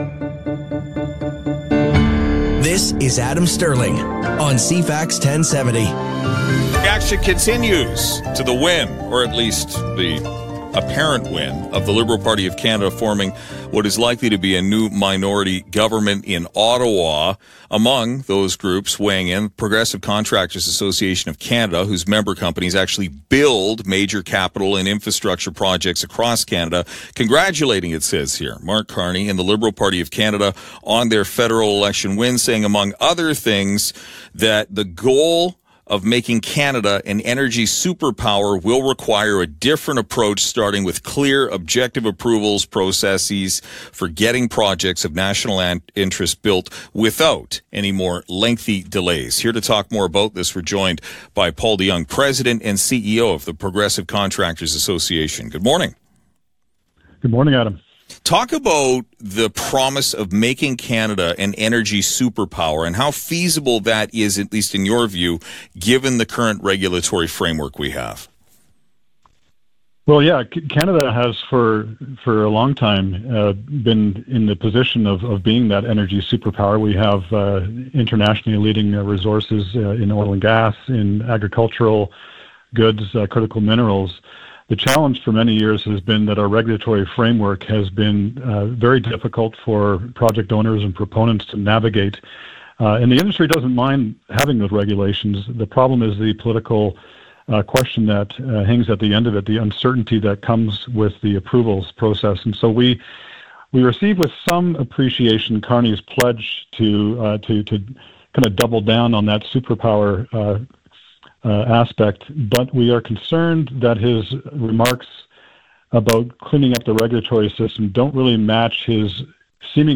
pdj-cfax-1070-interview.m4a